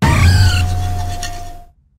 fluttermane_ambient.ogg